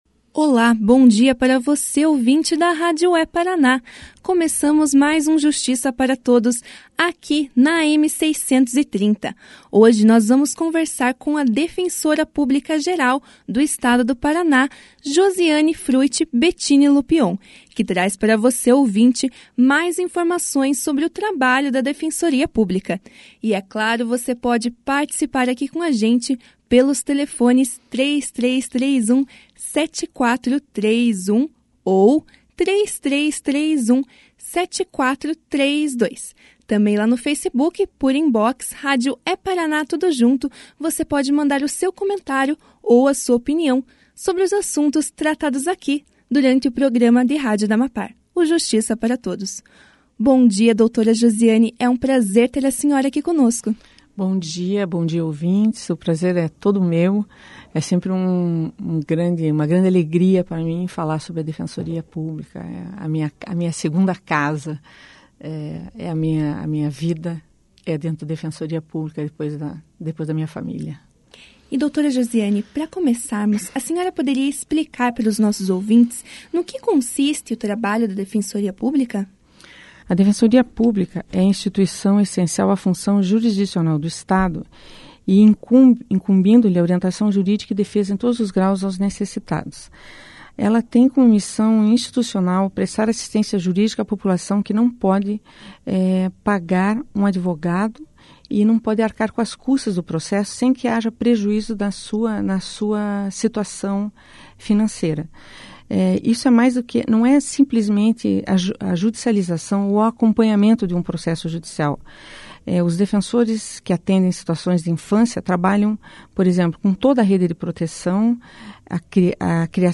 A defensora pública-geral do Estado do Paraná, Josiane Fruet Bettini Lupion, participou do programa de rádio da AMAPAR de hoje (21) e falou aos ouvintes sobre o trabalho da Defensoria Pública. Durante a entrevista ela explicou sobre a ideia de expandir o trabalho da Defensoria para o interior do Paraná e um dia chegar a atender todas as comarcas do Estado. Além disso, a defensora orientou a população sobre a documentação e os procedimentos necessários para o atendimento na Defensoria Pública.